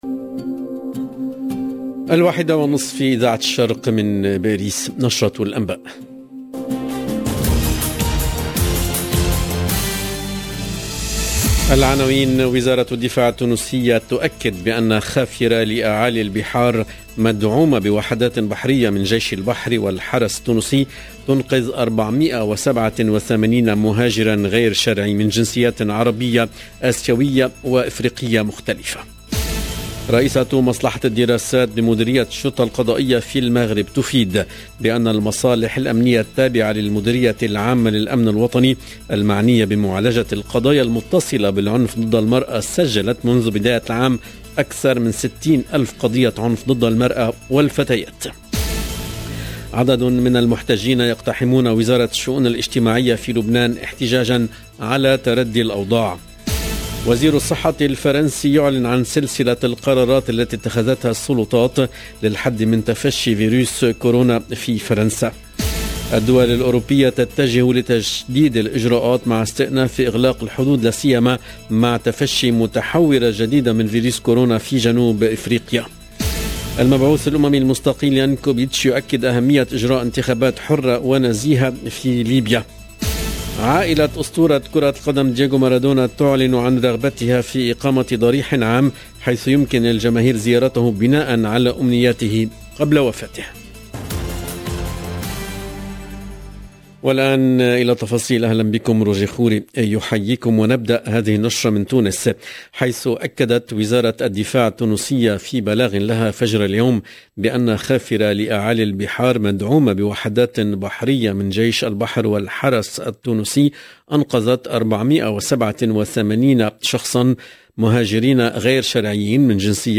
LE JOURNAL DE LA MI-JOURNEE EN LANGUE ARABE DU 26/11/21